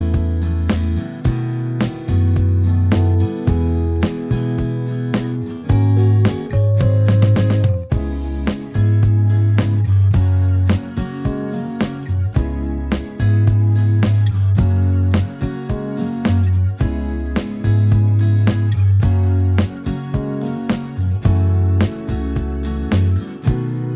Minus Electric Guitar Soft Rock 5:06 Buy £1.50